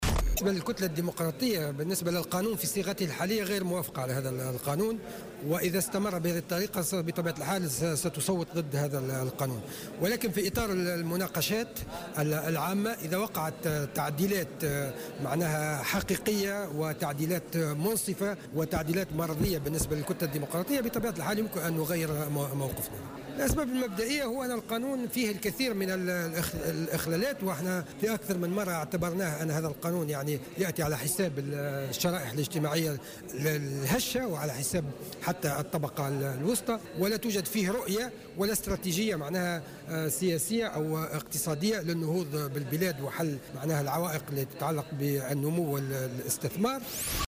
و أضاف في تصريح اليوم لمراسل "الجوهرة أف أم" أن القانون في شكله الحالي يتضمن عديد الاخلالات ويأتي على حساب الشرائح الاجتماعية الهشة والطبقة الوسطى، ولا توجد فيه استراتيجية لحل مشاكل النمو والاستثمار، وفق تعبيره.